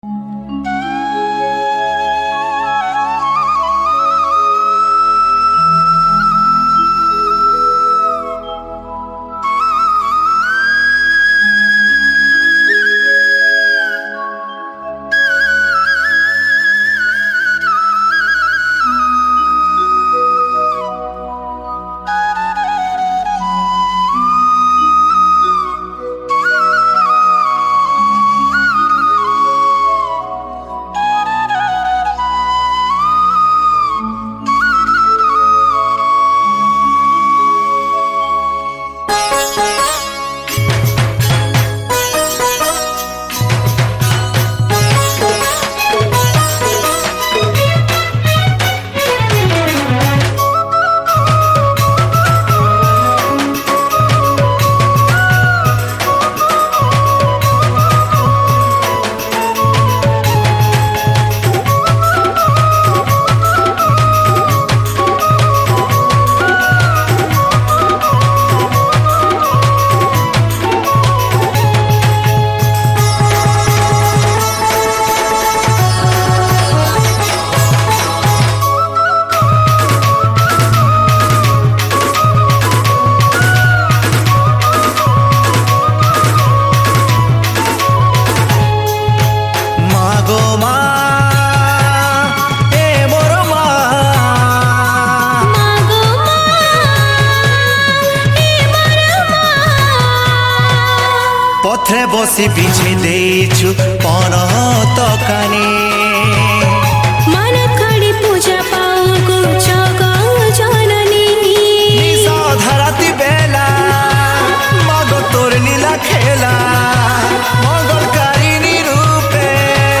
Sambalpuri Bhajan
Category: Sambalpuri Bhakti Songs 2022